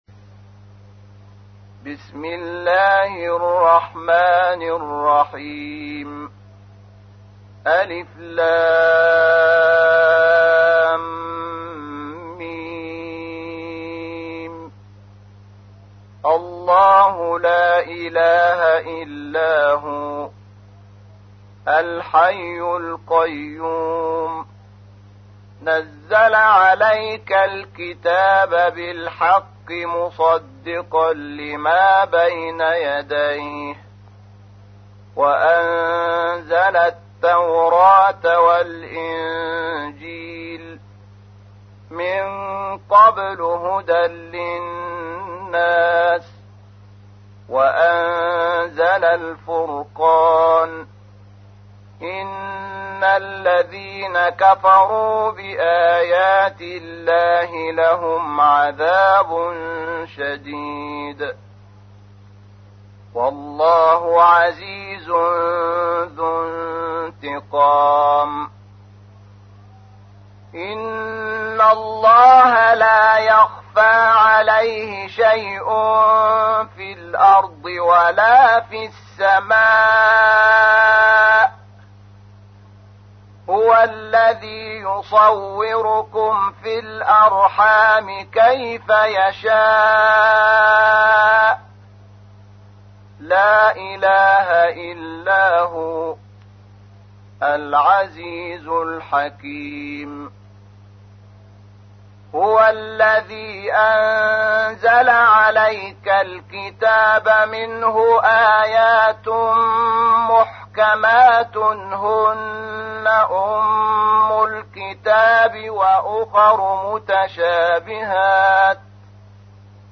تحميل : 3. سورة آل عمران / القارئ شحات محمد انور / القرآن الكريم / موقع يا حسين